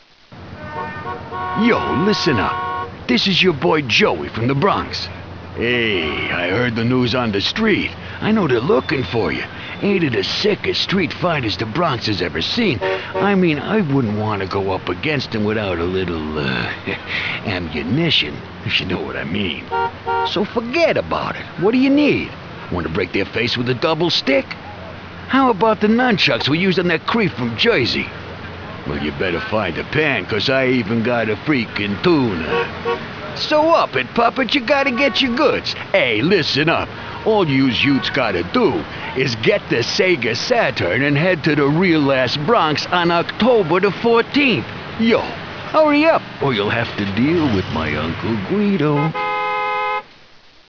Radio-Werbung